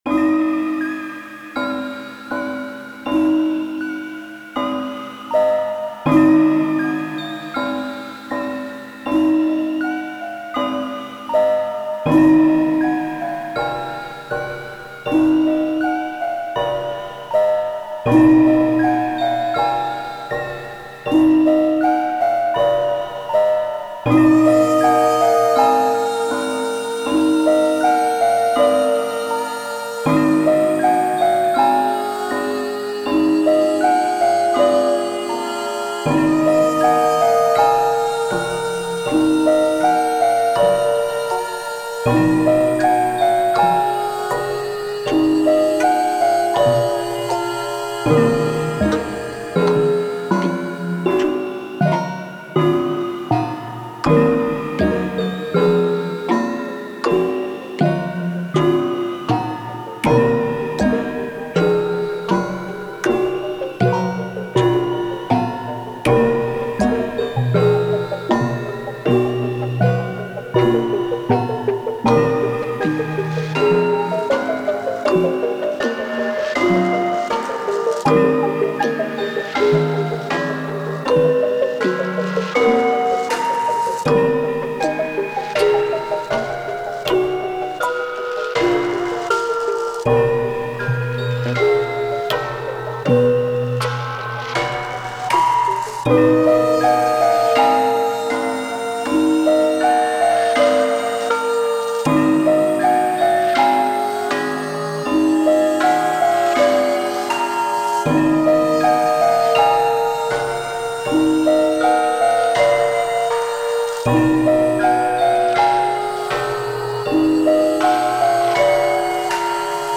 閉塞感のある残響や声が特徴的で、中盤の展開ではピッチやリズムの微妙な変化で不気味な気持ち悪さを出そうとしている。